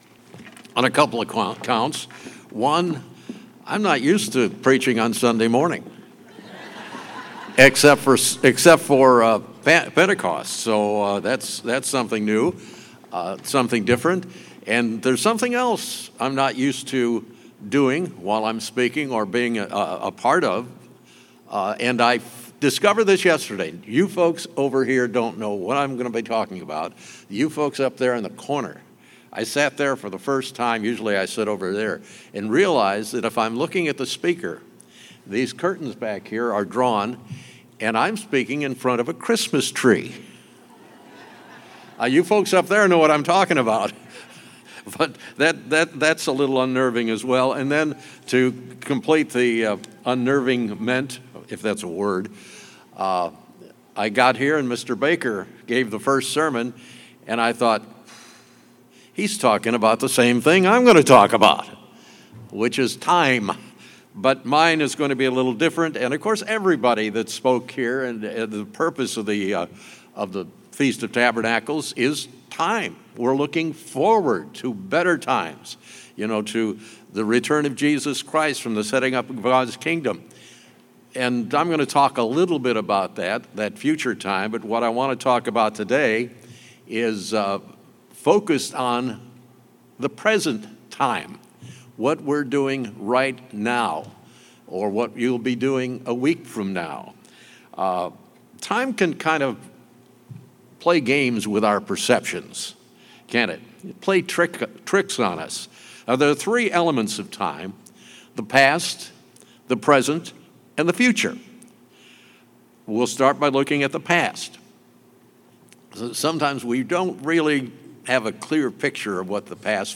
This sermon was given at the Branson, Missouri 2021 Feast site.